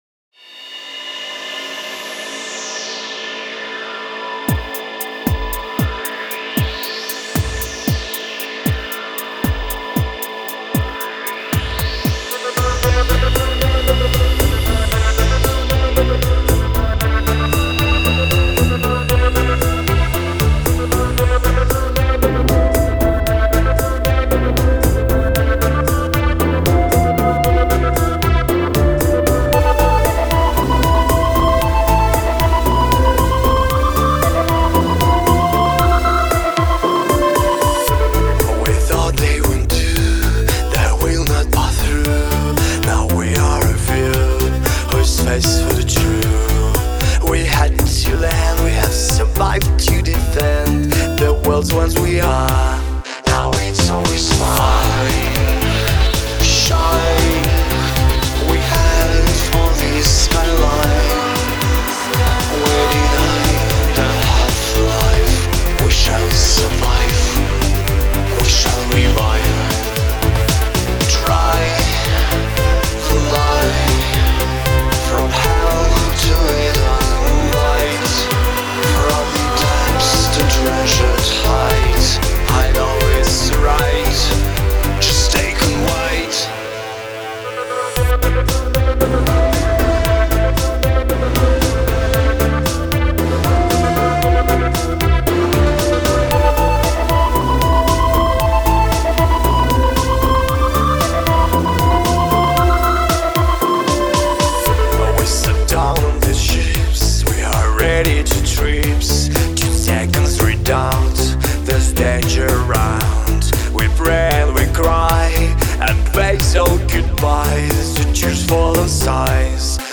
Genre: Electronic
Style: Electronic-pop